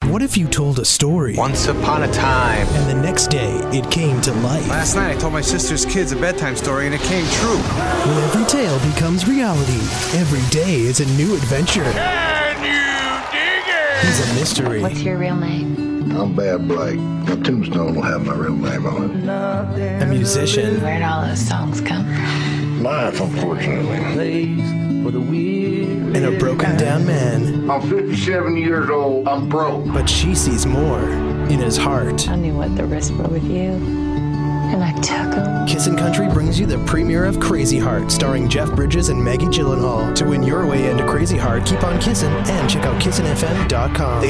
Male
My voice has "today's sound". It's young, fresh, cool, natural, conversational, relatable. I can do anything from laid back to hard sell and excited. I have a slight raspy and deep voice but can deliver upbeat young sounding copy with ease. I can do a cool, hip radio imaging voice from excited to smooth "mtv" type deliveries.
Movie Trailers